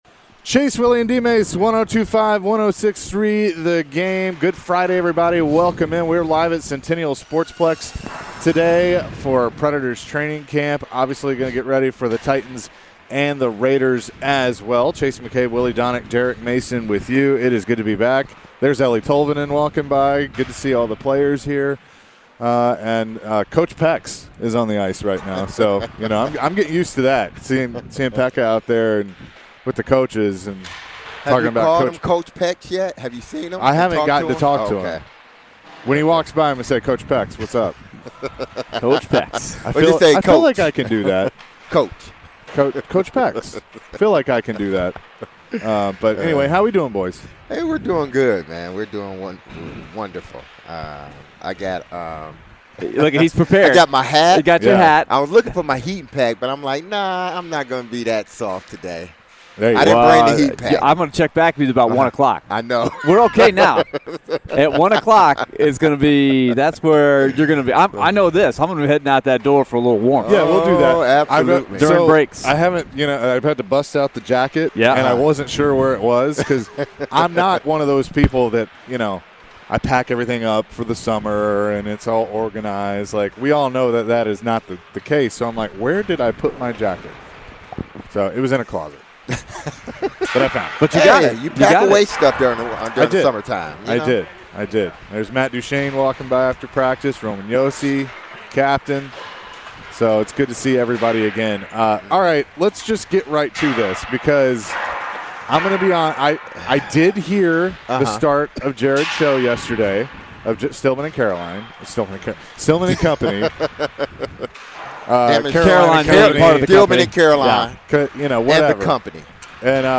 Hour 1: Live from Preds camp (9-23-22)